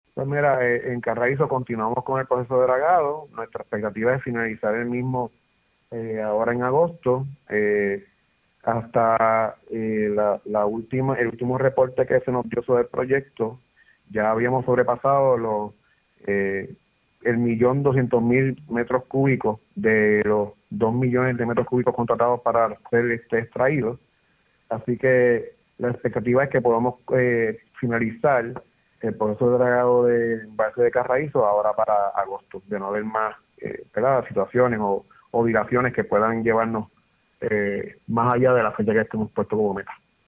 El presidente ejecutivo de la Autoridad de Acueductos y Alcantarillados (AAA), ingeniero Luis González Delgado, confirmó hoy, lunes, que en agosto estará listo el dragado del embalse de Carraízo.